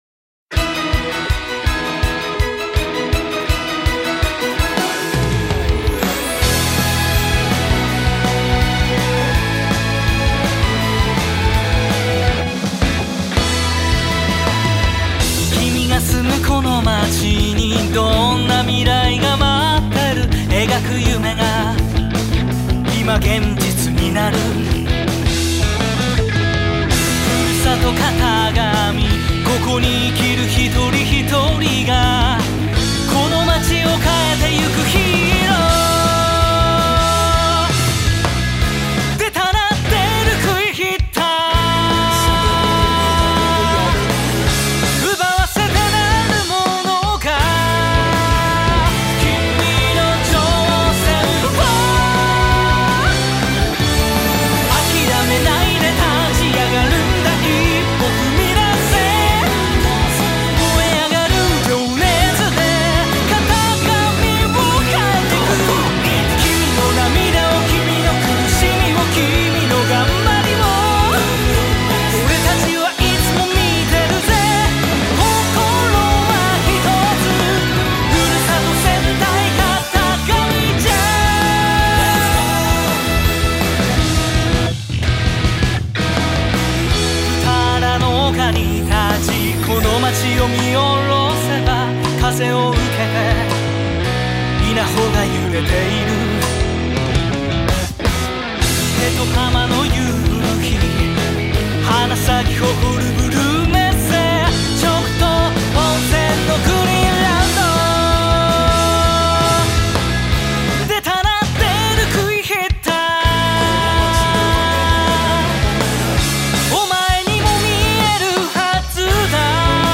頑張る人を応援し、落ち込んでいるときには勇気をもらえる曲となっています。